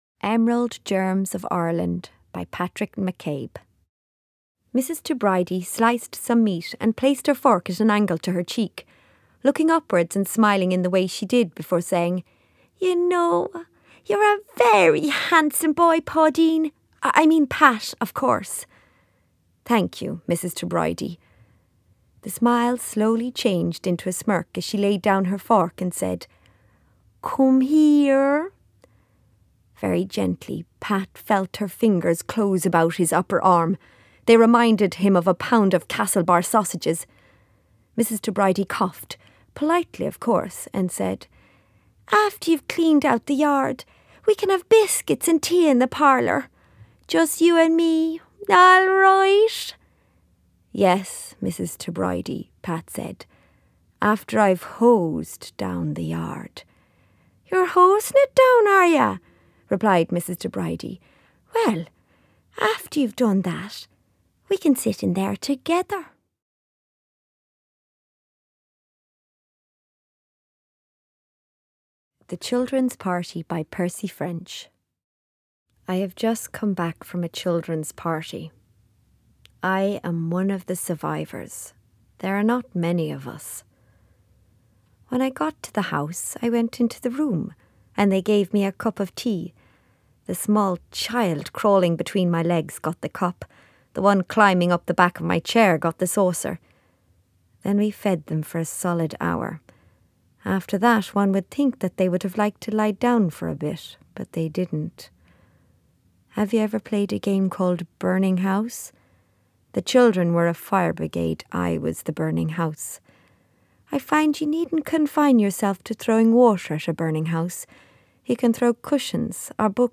Voice clips
voicereel.mp3